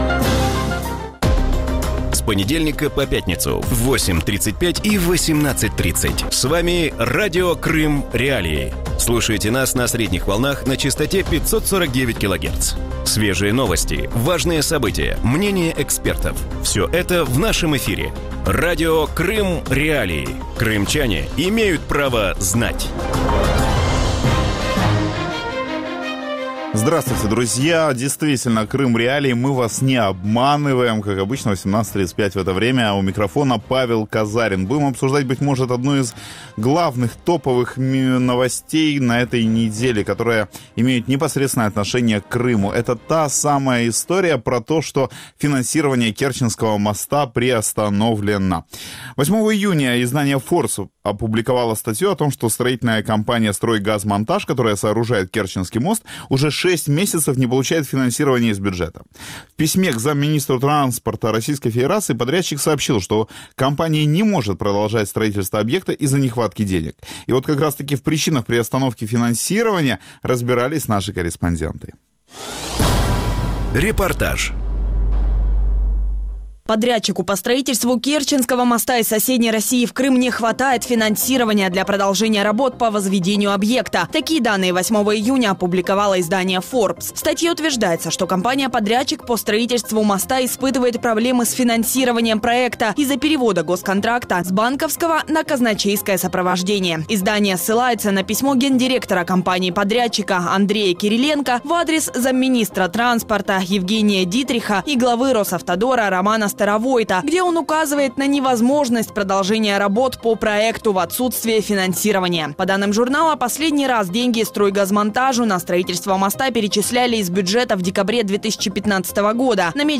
В вечернем эфире Радио Крым.Реалии обсуждают задержку строительства Керченского моста.